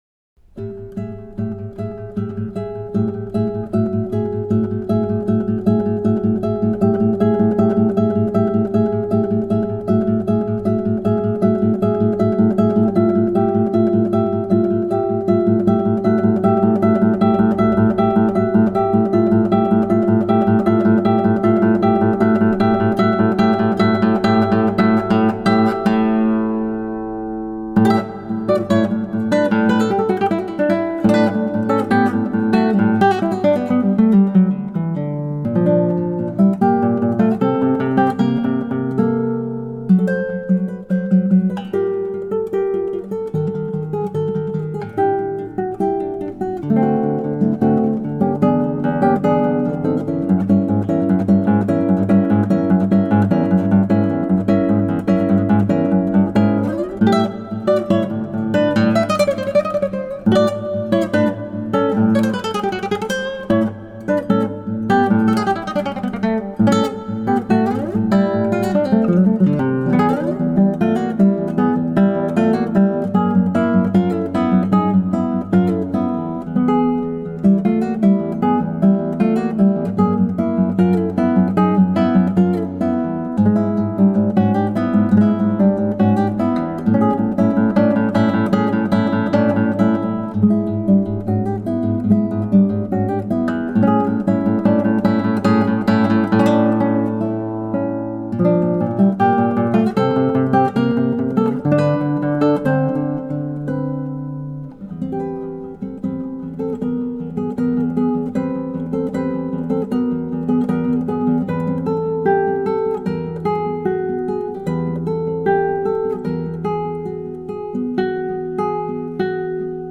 composizione chitarristica
Potete ascoltare il brano, dal sapore contemporaneo e molto delicato, eseguito dallo stesso autore: